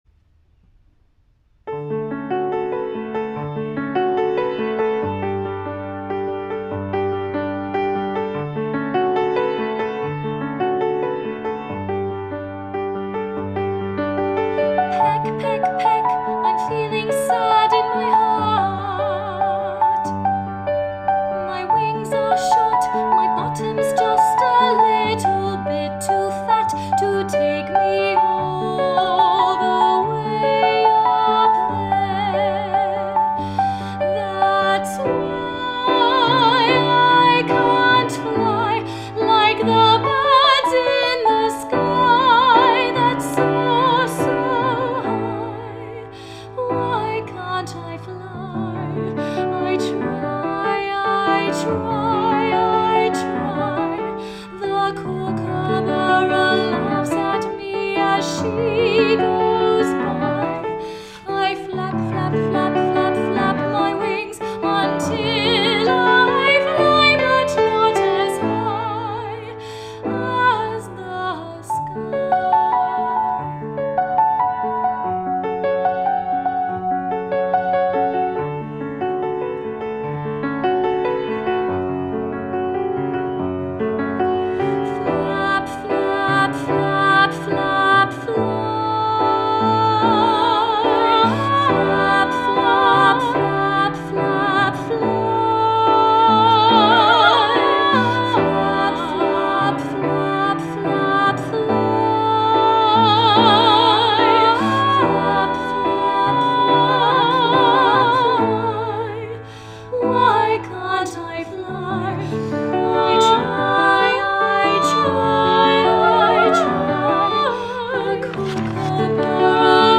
Hear the live demo below the score.
SA with piano